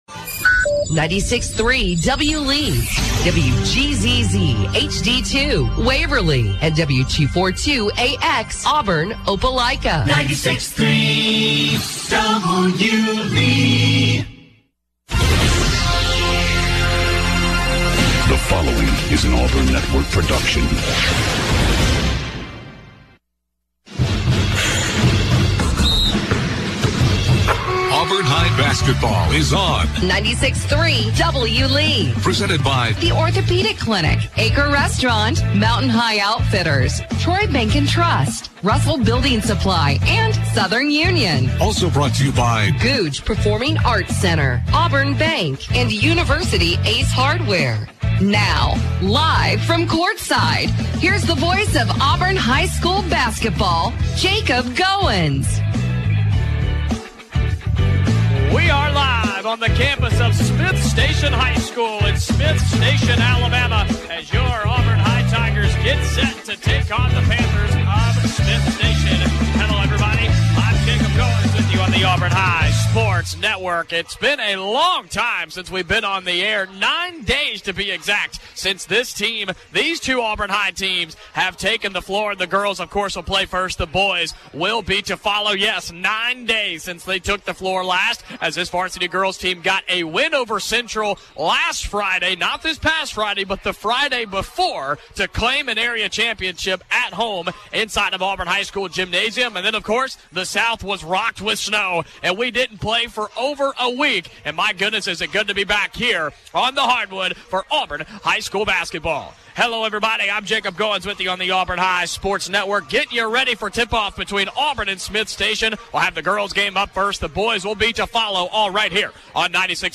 calls Auburn High's game against Smiths Station. The Tigers won 62-32.